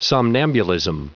Prononciation du mot somnambulism en anglais (fichier audio)
Prononciation du mot : somnambulism